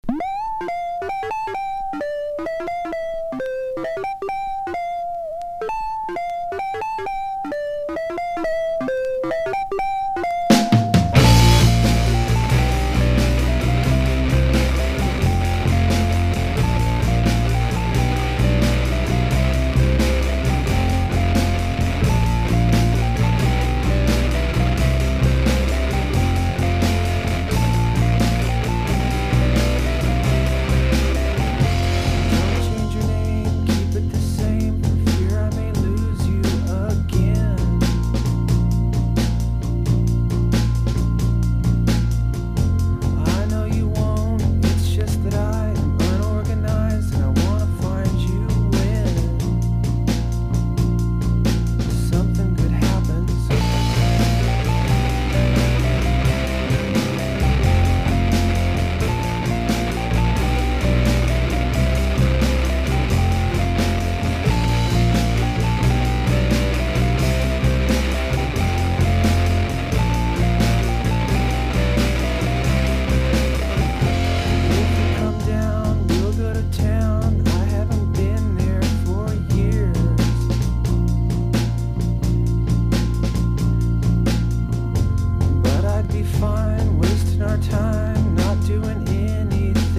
切ないチープなシンセと歪んだギター・サウンドが印象的なメロディーが最高!!